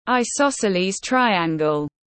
Hình tam giác cân tiếng anh gọi là isosceles triangle, phiên âm tiếng anh đọc là /aɪˌsɒs.əl.iːz ˈtraɪ.æŋ.ɡəl/.
Isosceles triangle /aɪˌsɒs.əl.iːz ˈtraɪ.æŋ.ɡəl/